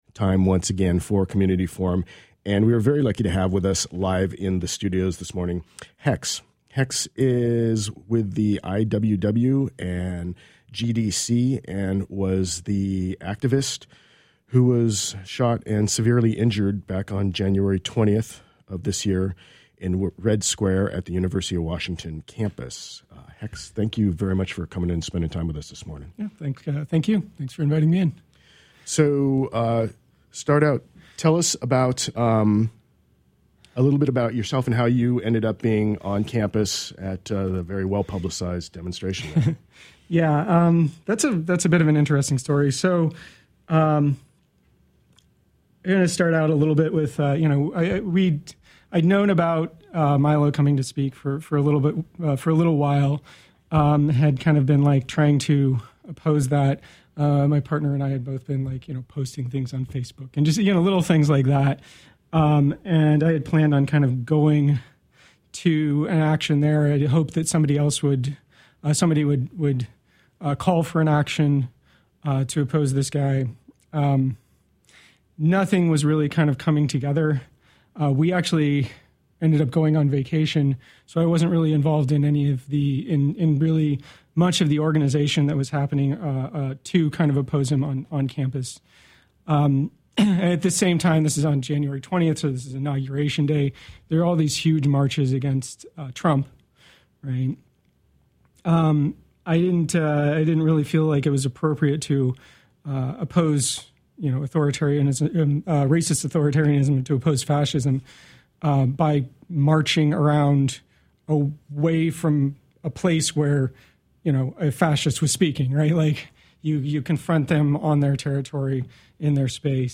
Interview with IWW/GDC Survivor of UW Shooting